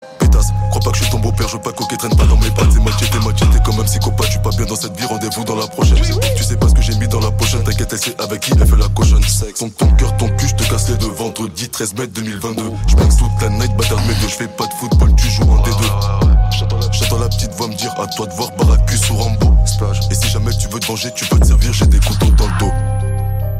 Catégorie: Rap - Hip Hop